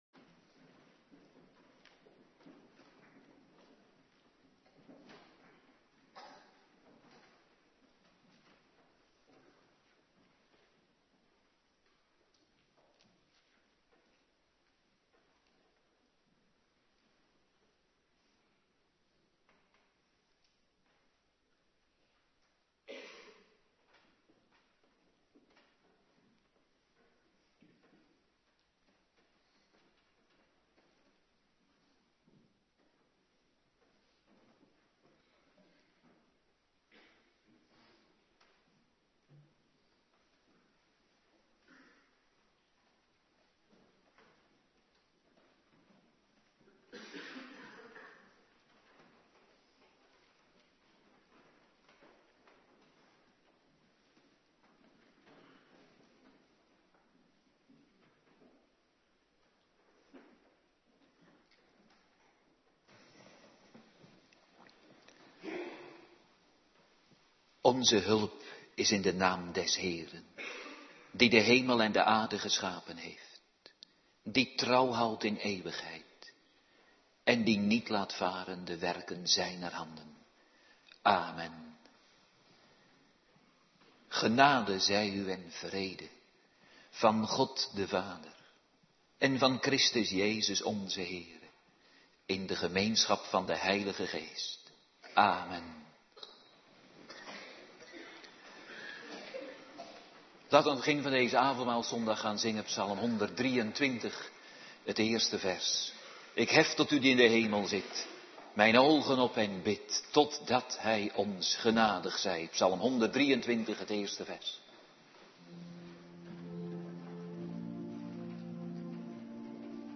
Morgendienst Heilig Avondmaal
09:30 t/m 11:00 Locatie: Hervormde Gemeente Waarder Agenda